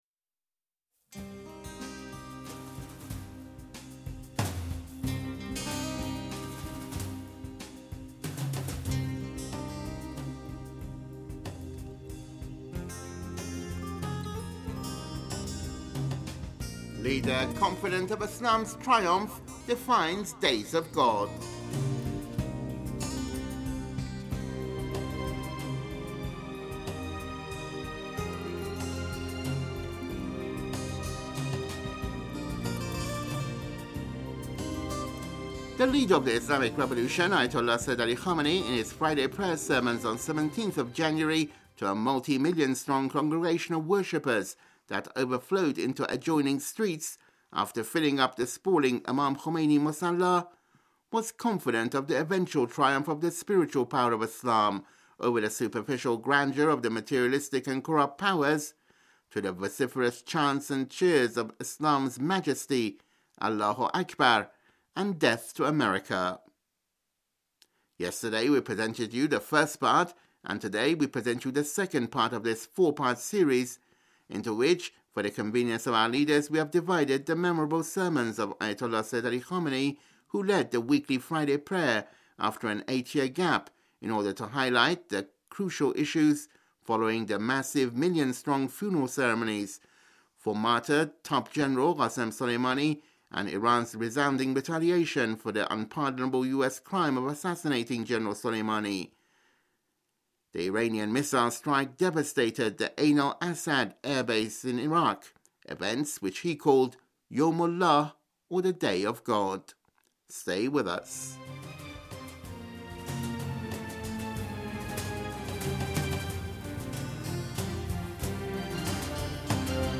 Here is the second part of the four parts of the memorable sermons of Leader, who led the weekly Friday Prayer on January 17 after an 8-year gap in order t...